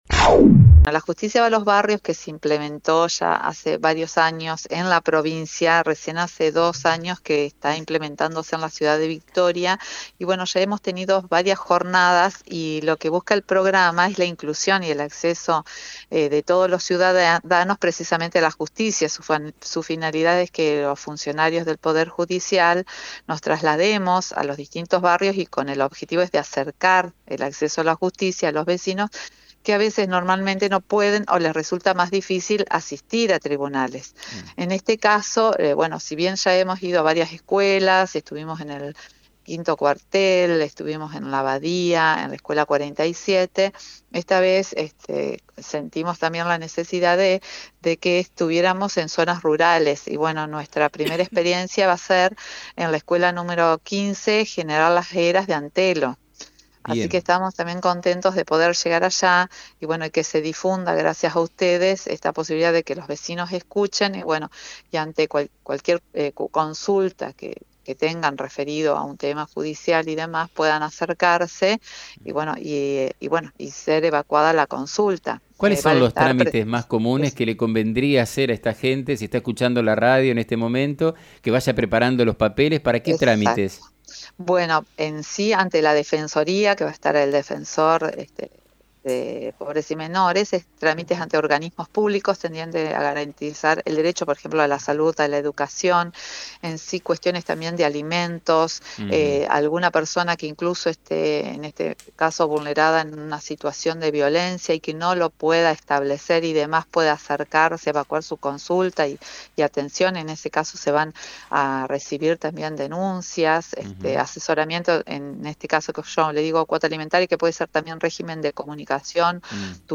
En diálogo con FM 90.3